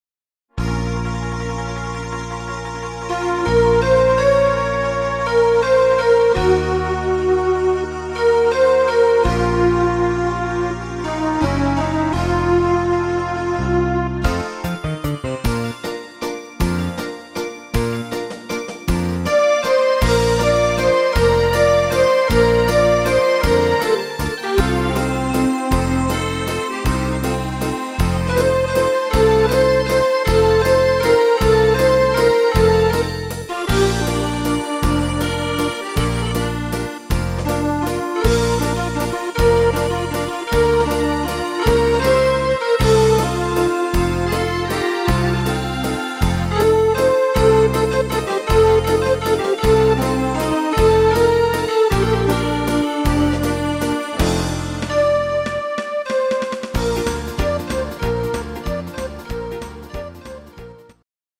Walzer - Rock'n Roll Version